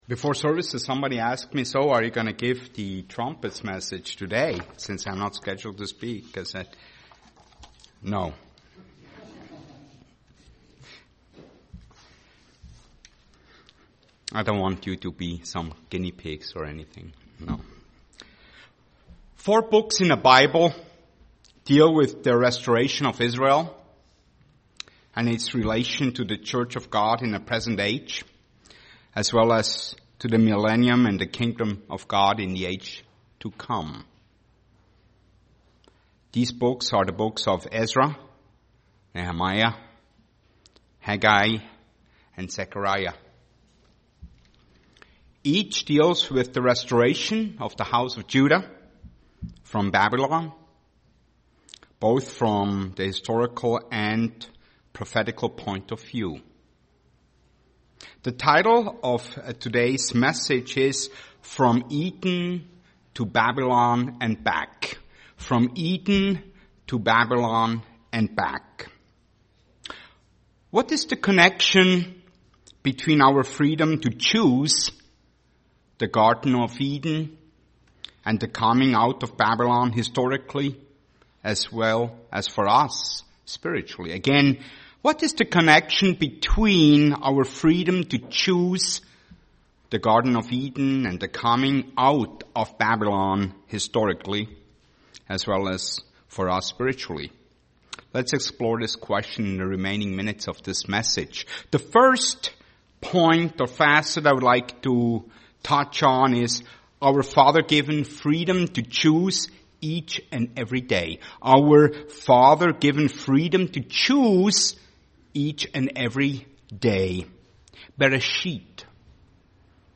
Given in Twin Cities, MN
UCG Sermon Eden Babylon Studying the bible?